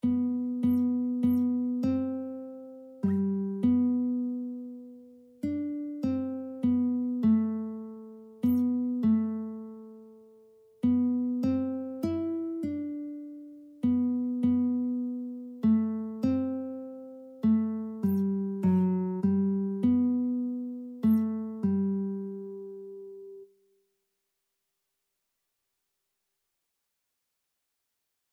Christian
3/4 (View more 3/4 Music)
Classical (View more Classical Lead Sheets Music)